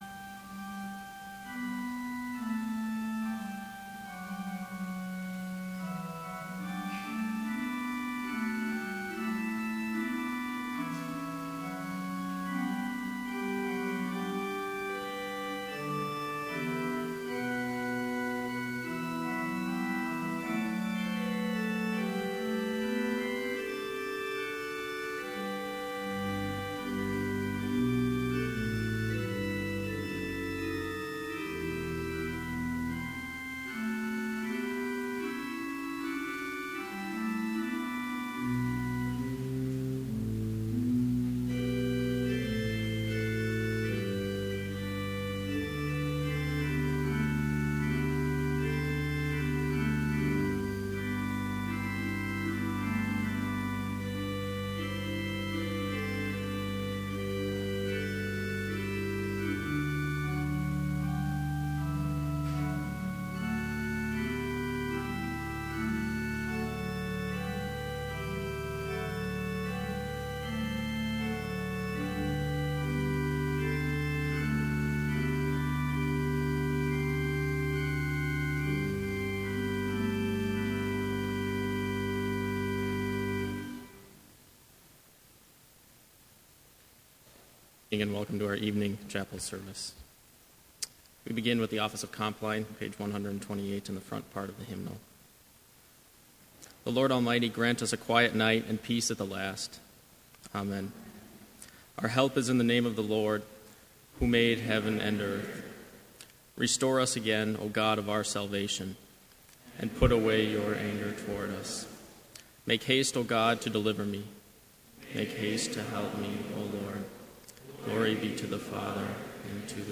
Vespers service held at Bethany Lutheran College on September 30, 2015, (audio available) with None Specified preaching.
Complete service audio for Evening Vespers - September 30, 2015